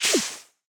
Minecraft Version Minecraft Version 25w18a Latest Release | Latest Snapshot 25w18a / assets / minecraft / sounds / mob / pufferfish / blow_out1.ogg Compare With Compare With Latest Release | Latest Snapshot
blow_out1.ogg